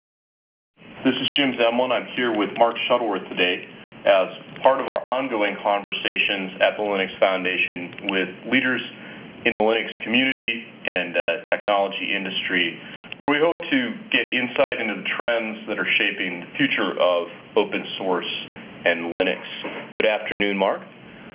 As reference speech fragment the part of Mark Shuttleworth's interview was given.
Then we pass this speech sample through wav2rtp with filter "independent packet losses" turned on and compare source and degraded file with pesqmain utility.
Table 1: Independent network losses influation on the output speech quality (G.729u)